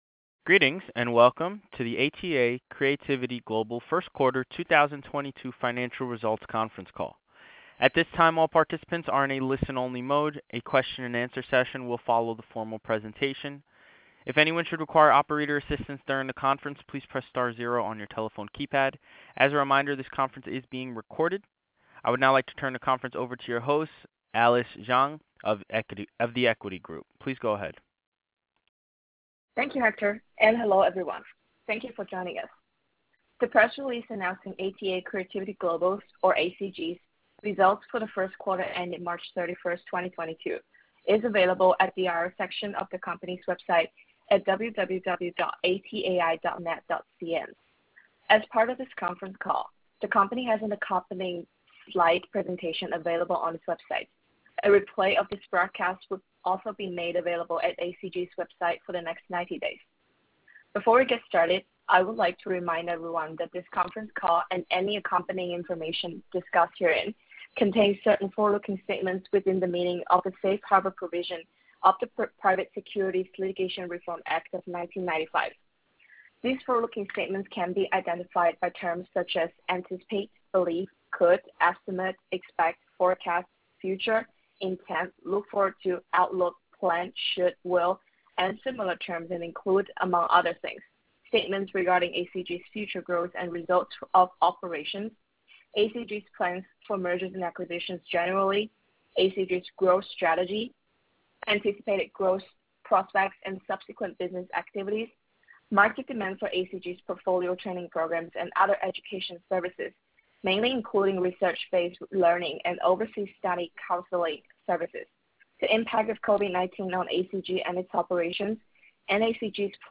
Q1 Fiscal Year 2022 Earnings Conference Call